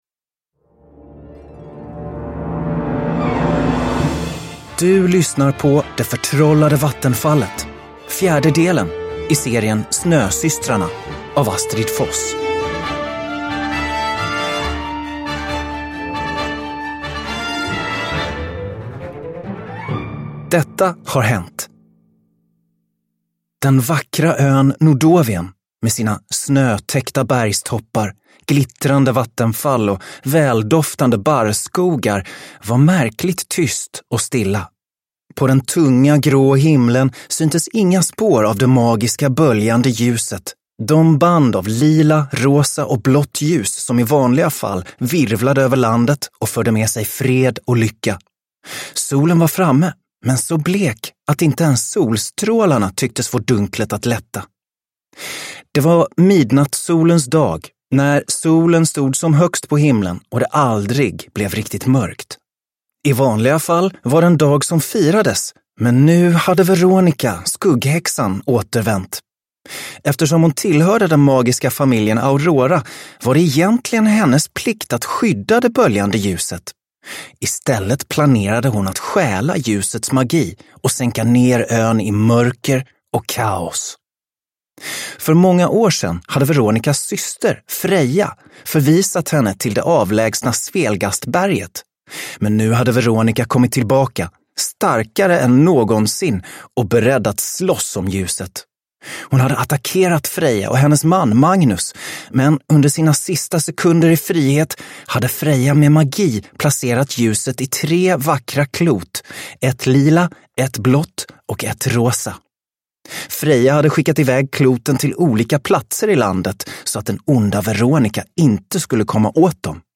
Det förtrollade vattenfallet – Ljudbok – Laddas ner